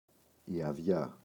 αδειά, η [aꞋðʝa] – ΔΠΗ